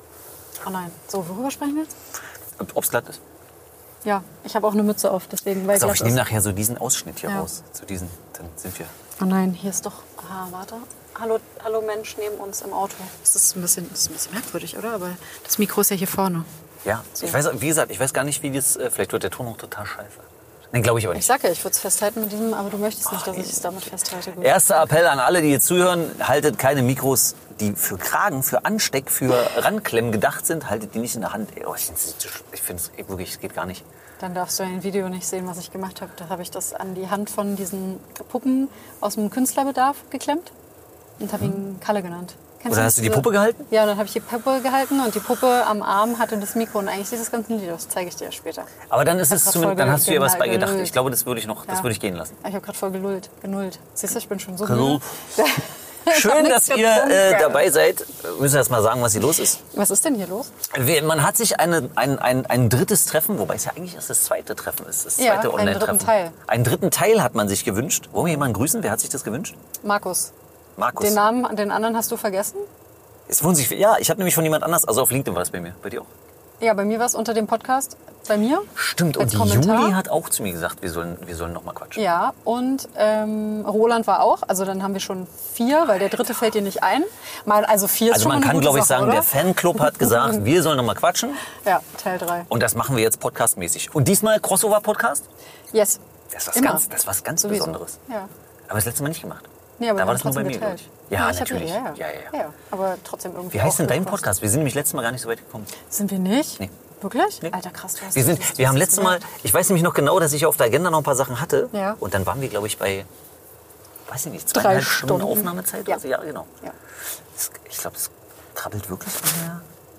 Live-Podcast im Bus mit Publikum?